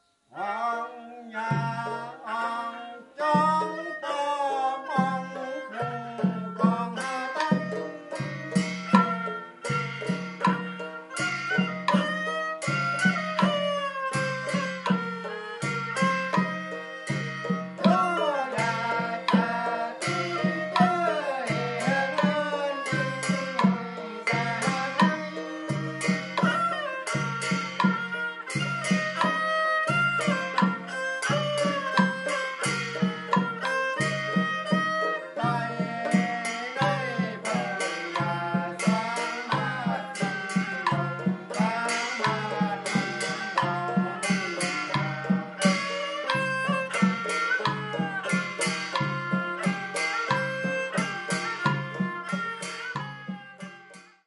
Shan music
Shan band horn fiddle, banjo, drum and percussion accompany the singer
shan band.mp3